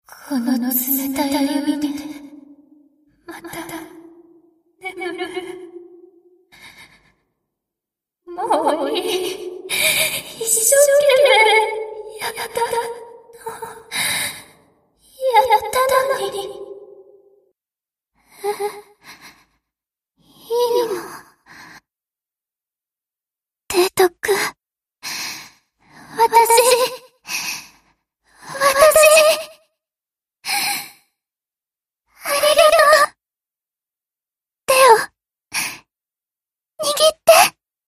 棲艦語音7